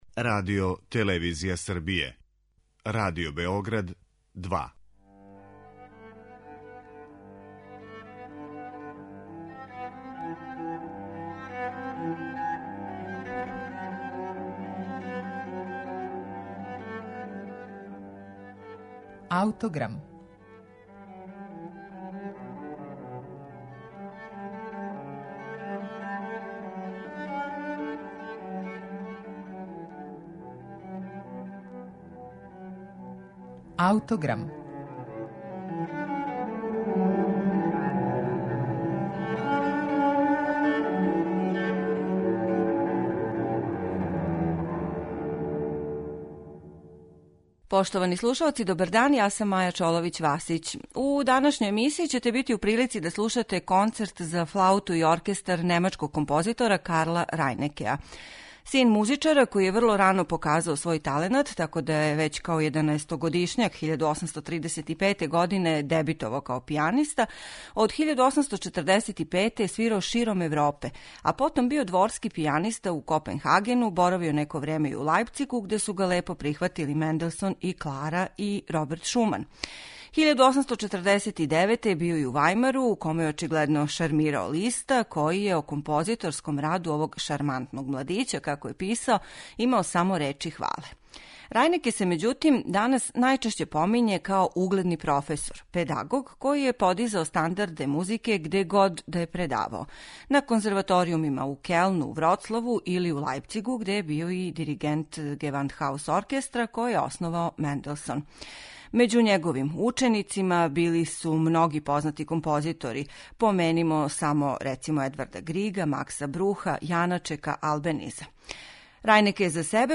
Концерт за флауту и оркестар је најпопуларнији део опуса немачког композитора Карла Рајнекеа.
Настао је 1908. године, али је по свом стилу романтичарско остварење и то готово једино у репертоару за флауту које се данас изводи на интернационалној музичкој сцени.
Дело посвећено флаутисти Максимилијану Шведлеру, плени изразитим лиризмом и ведрином и сведочи о таленту који је Рајнеке поседовао за мелодију и оркестрацију, као и о његовом разумевању извођачког потенцијала солистичког инструмента.
Слушаћете га у итерпретацији Оријела Николеа и Лајпцишког Гевандхаус орекестра, којим диригује Курт Мазур.